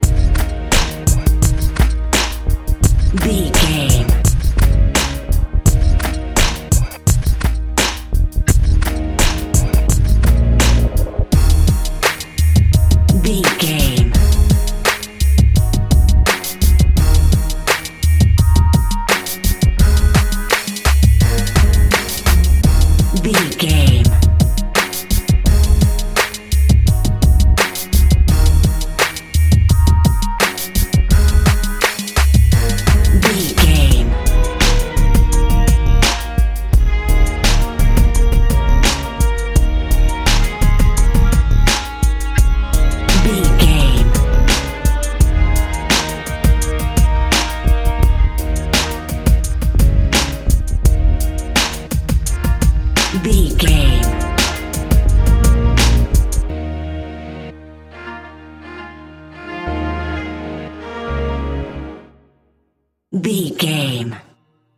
Epic / Action
Aeolian/Minor
E♭
drum machine
synthesiser
funky
hard hitting